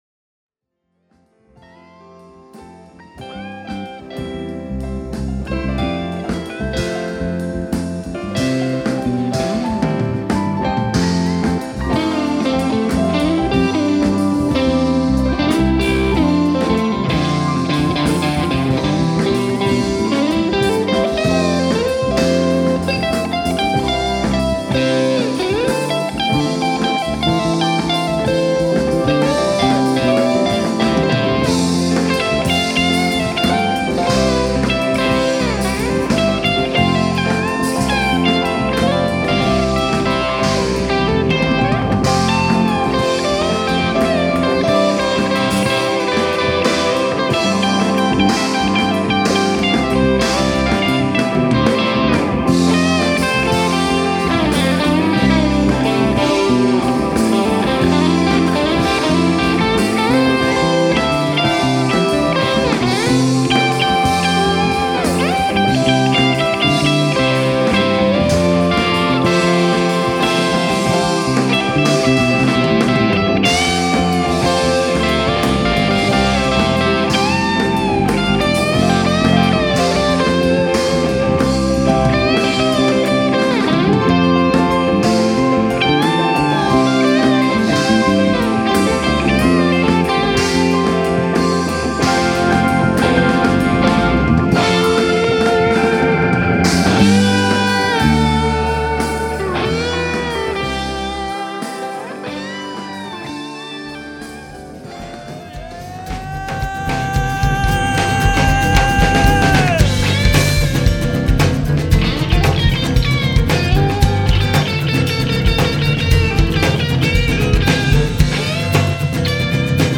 Studio solo clips
StudioSolosFinal.mp3